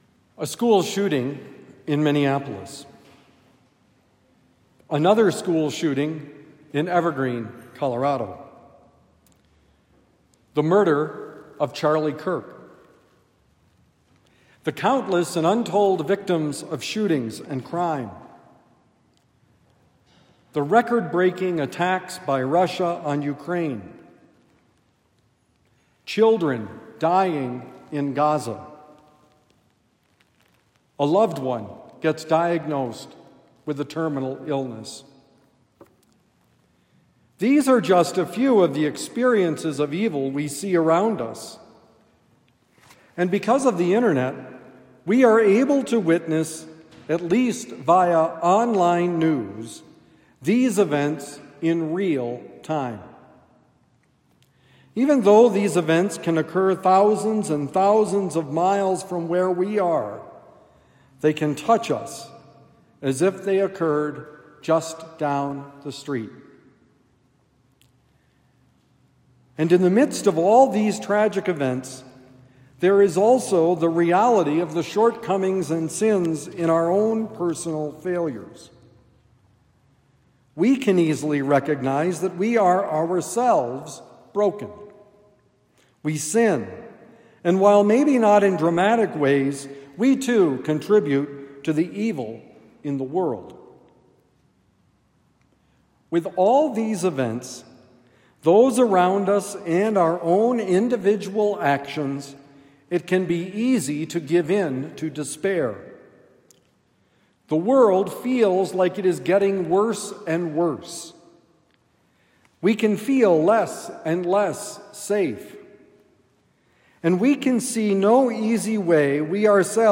The Problem of Evil: Homily for September 14, 2025 – The Friar